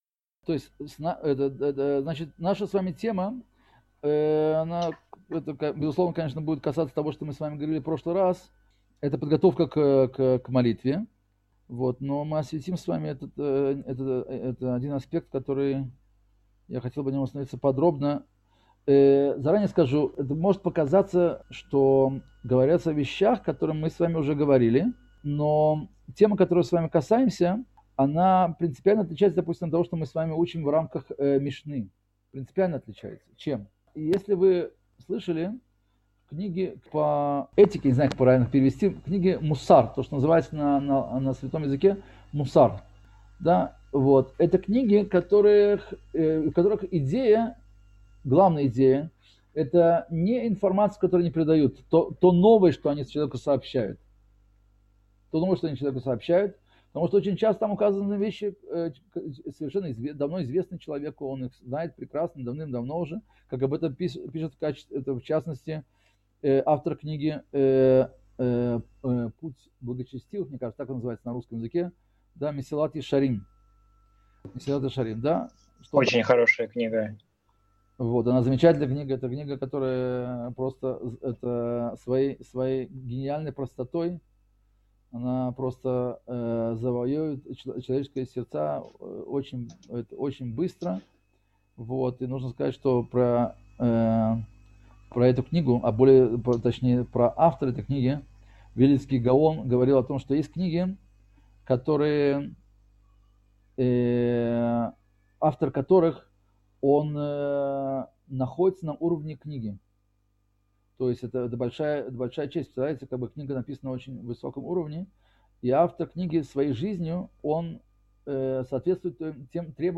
Цикл уроков по недавно вышедшей в свет книге рава Шимшона Давида Пинкуса «Врата в мир молитвы»